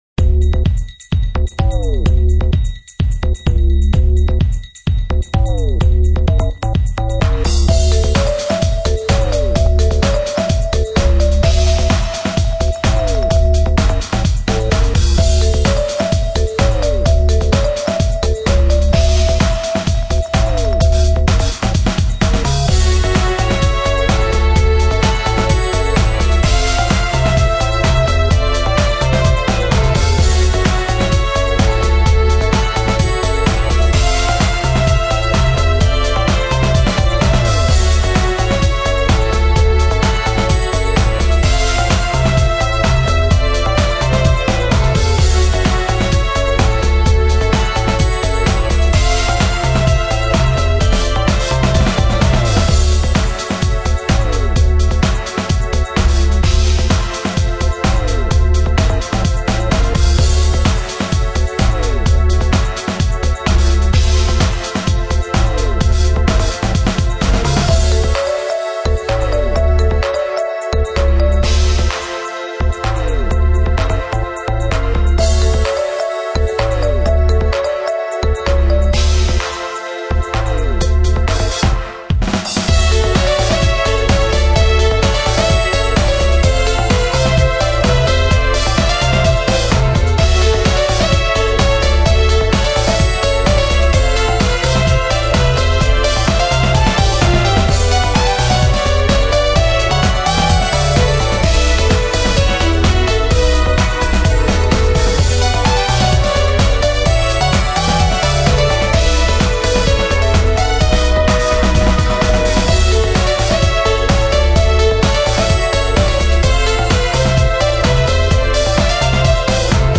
明るい気分と暗い気分の「blue」と捉えてくれれば・・・。